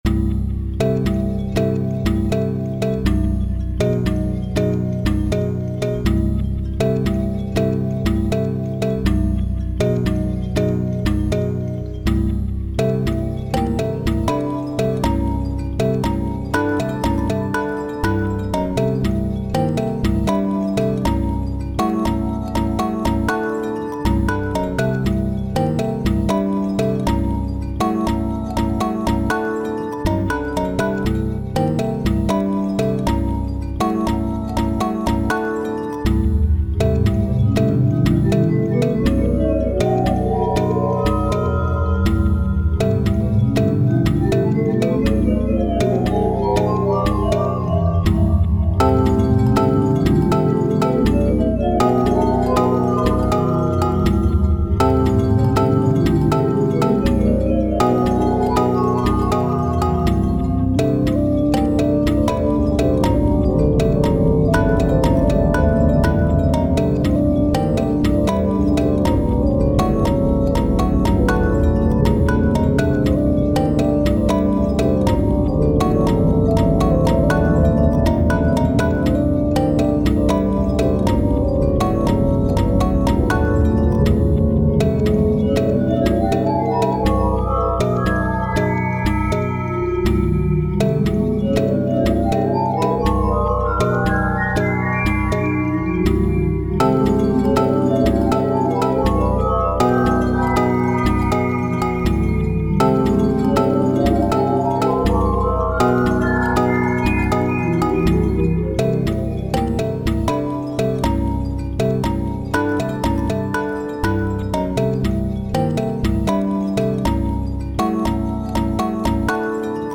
謎解きシーンをイメージしたBGM。 同じ音程を連続して使用することでニュートラルで不思議な雰囲気を出している。
補助的にテクスチャを使用することで不思議な雰囲気のバリエーションを作っている。